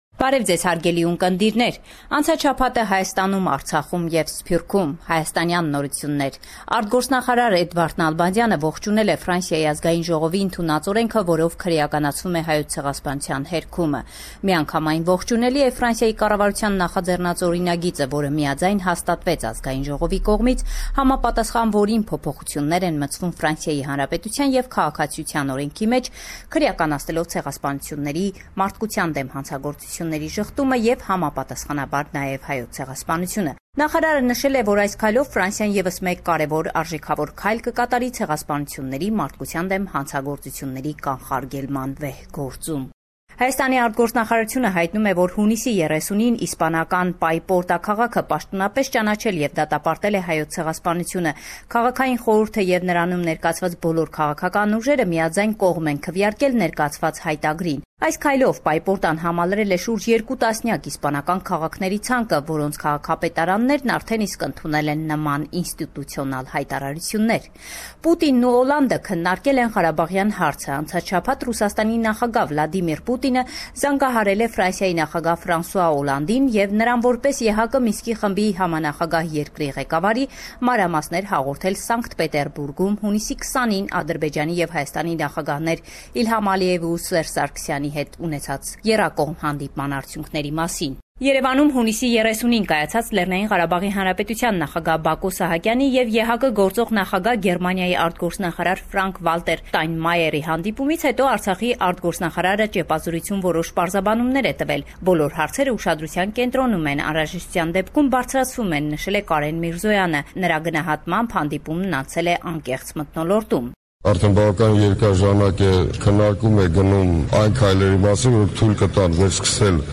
LATEST NEWS – 5 July 2016